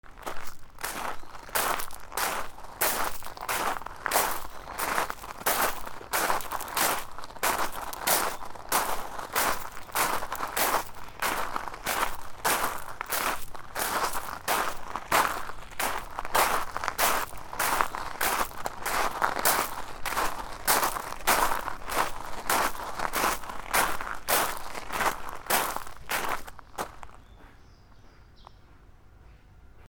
砂利を歩く 細かくて深めの砂利 遅め
/ I｜フォーリー(足音) / I-150 ｜足音 外1 土・グラウンド
『ザッザ』